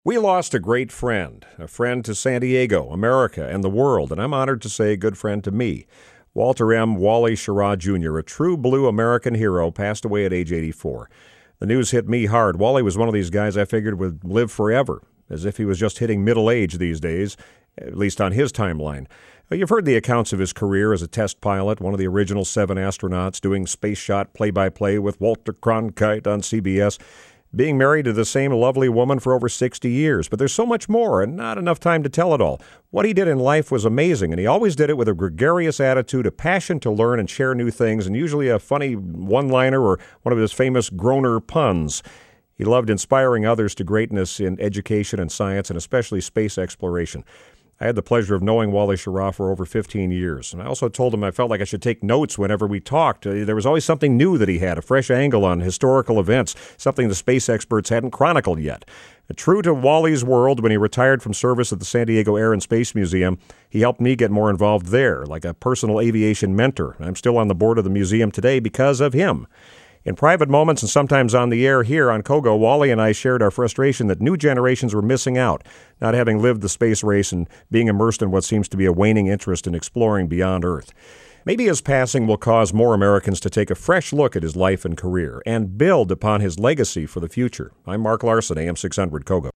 Radio Commentary